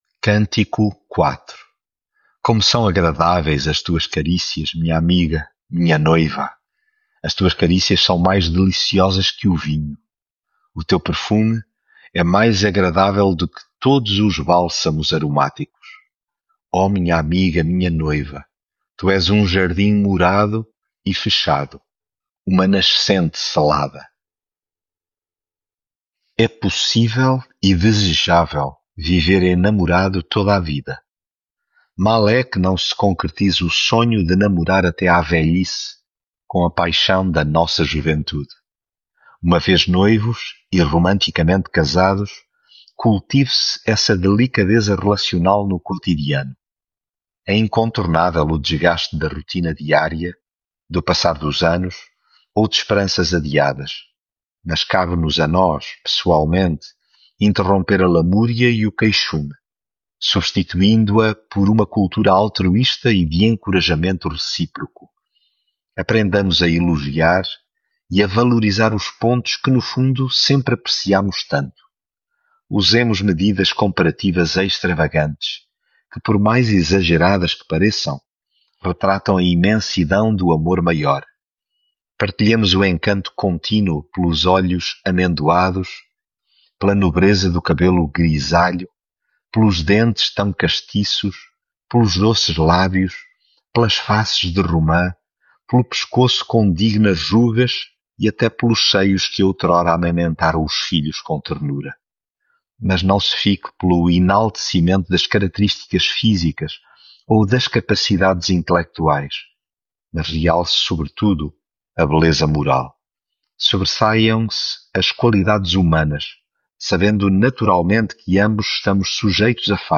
Devocional
Leitura em Cantares 4